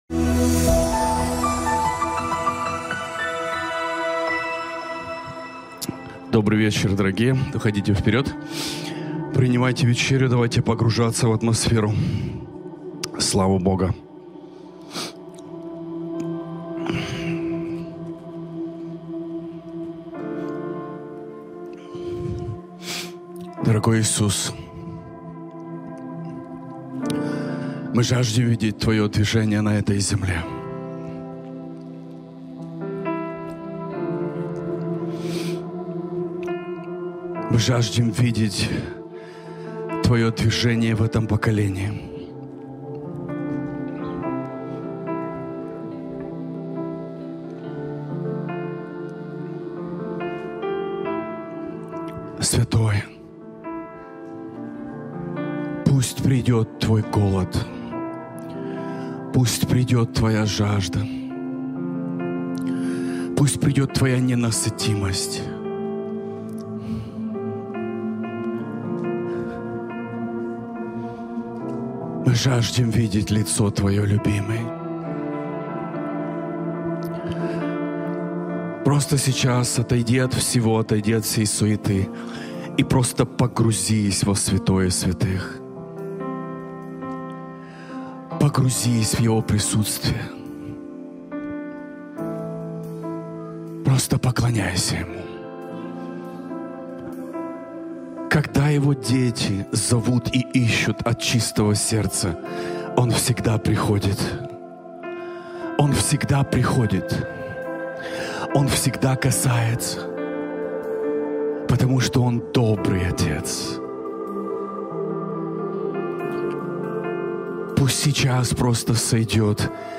Transformation Center Молитва 827